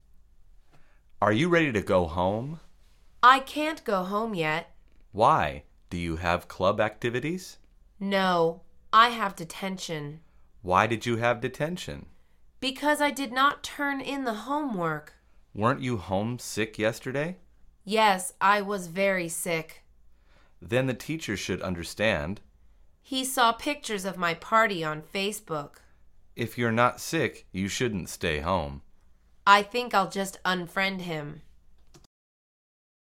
در واقع، این مکالمه مربوط به درس شماره نهم از فصل شبکه اجتماعی از این مجموعه می باشد.